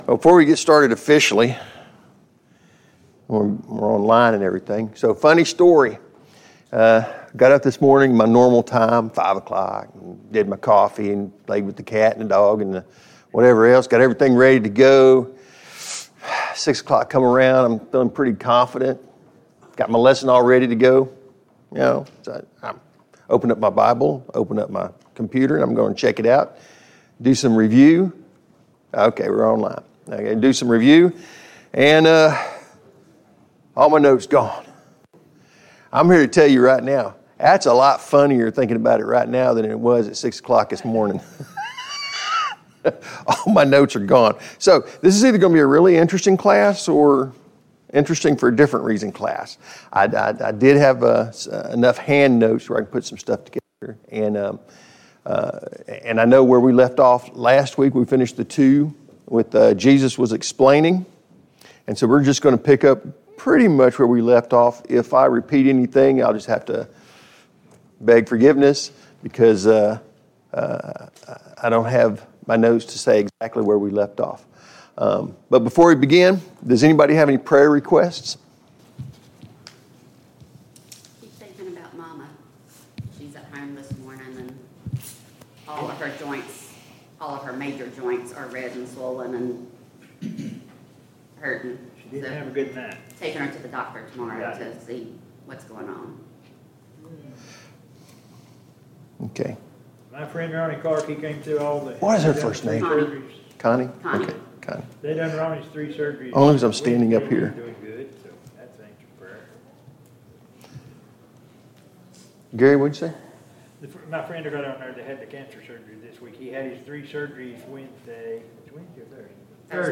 Mark 4:1-20 Service Type: Sunday Morning Bible Class Topics: The Parable of the Sower « 70.